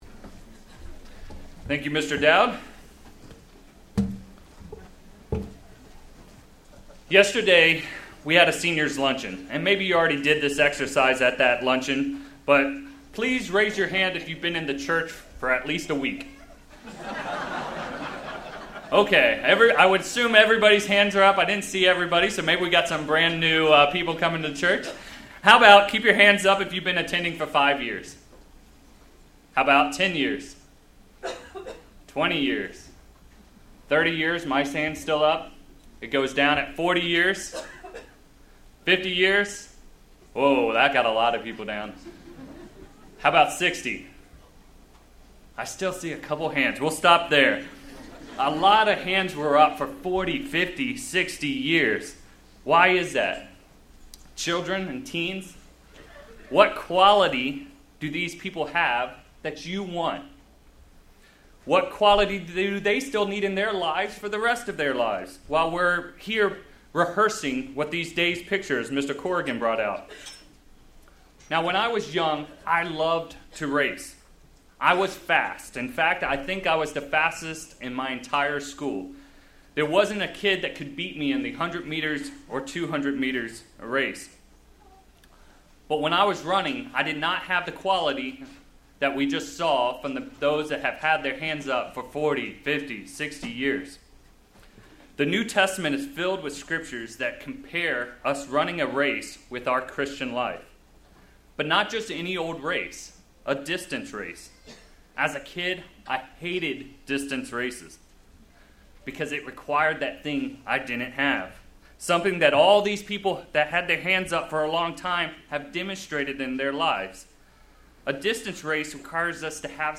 This sermon was given at the Victoria, British Columbia 2016 Feast site.